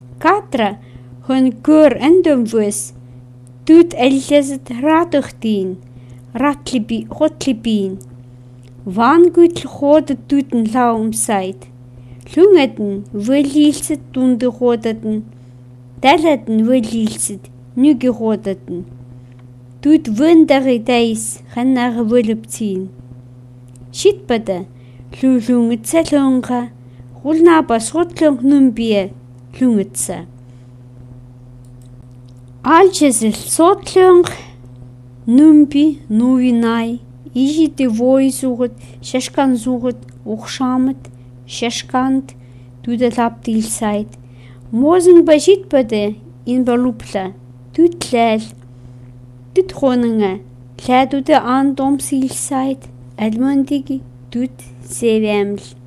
prose (pro)